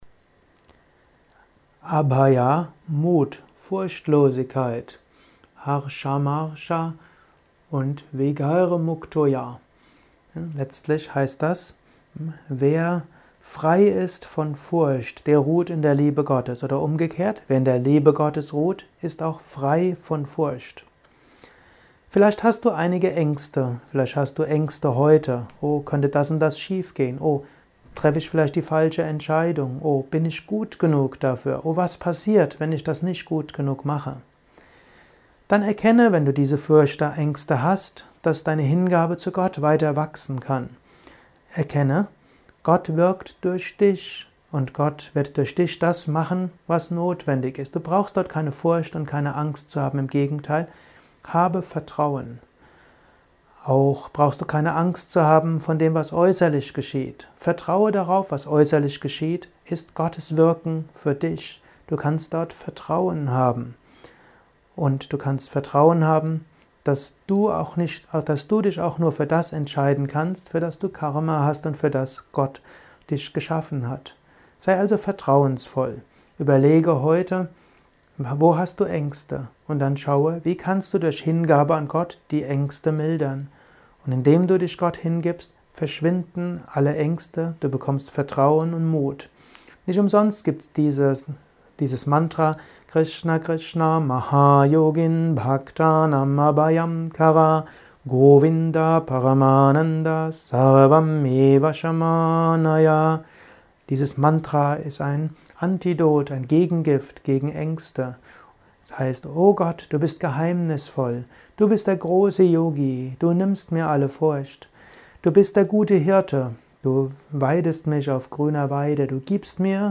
Dies ist ein kurzer Kommentar als Inspiration für den heutigen